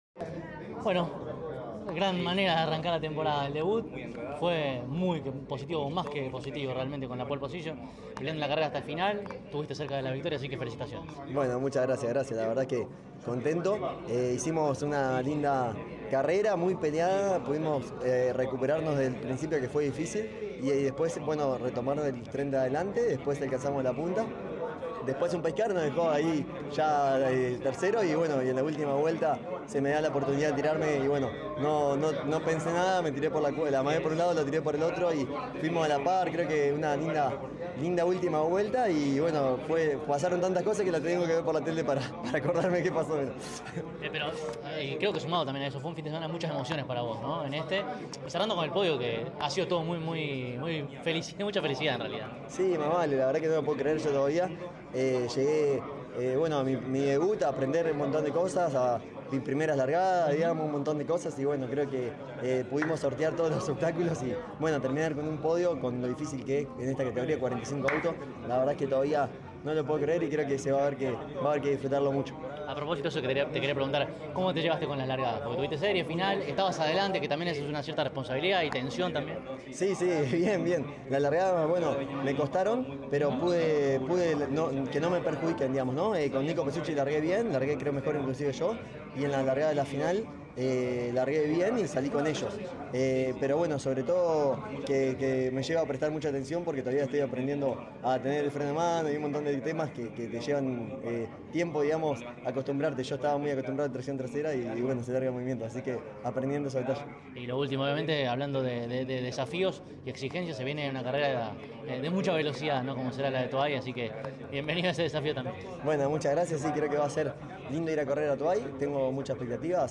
CÓRDOBA COMPETICIÓN estuvo presente en el trazado platense y dialogó con los protagonistas más importantes al cabo de cada la final de la clase mayor.